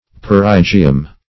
Perigee \Per"i*gee\, Perigeum \Per`i*ge"um\, n. [NL. perigeum,